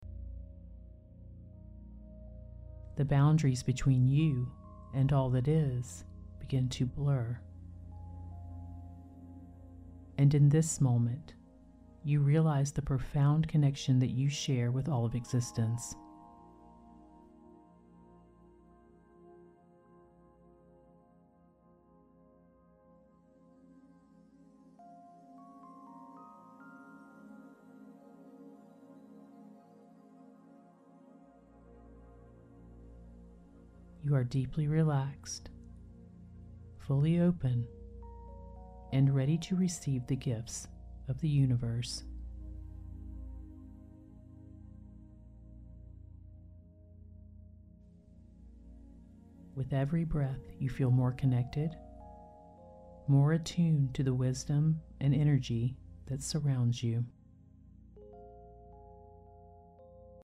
Discover a New Way to Transform: This ‘Quantum Jumping’ Hypnosis combines the potent synergy of theta binaural beats and guided visualization to unlock the depths of your subconscious mind.
Theta Binaural Beats for Deep Engagement: Accompanied by a theta binaural soundtrack, this hypnosis session is designed to transport you into a state of profound subconscious exploration, optimizing your mind’s receptivity to change and creating a deeper connection with your inner self.
This version includes more hypnotic suggestions and extended pauses for absorption, ensuring deeper impressions on your subconscious mind.